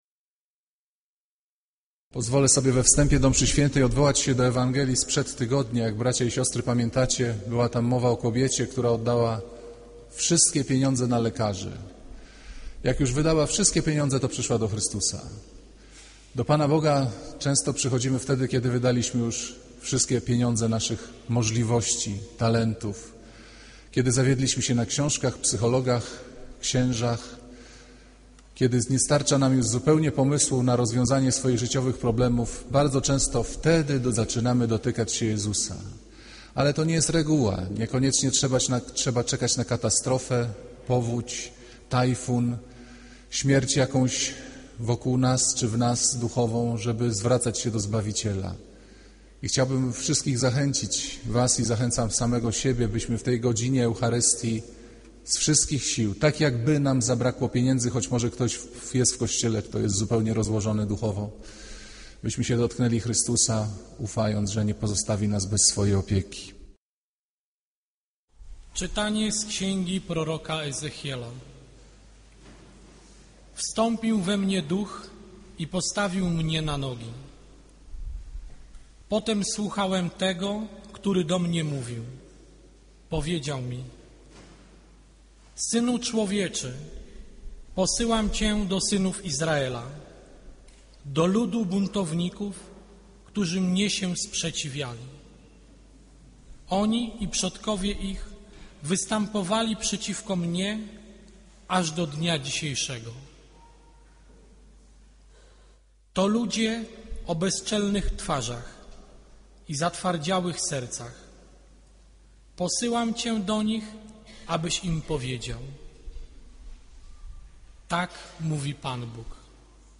Kazanie z 18 października 2009r.